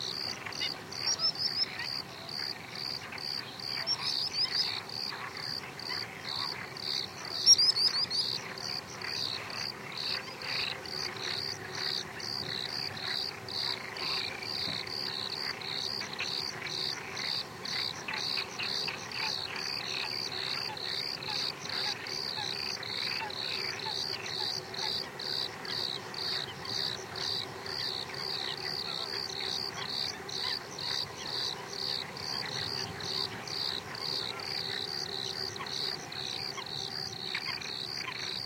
描述：蛙类的近距离拍摄，背景是黑翅长脚鹬的叫声和远处车辆的轰鸣声。录制于西班牙南部的多纳纳沼泽地。舒尔WL183，Fel BMA2前置放大器，PCM M10录音机
标签： 叫声 道纳拿 现场记录 青蛙 沼泽 南西班牙 弹簧
声道立体声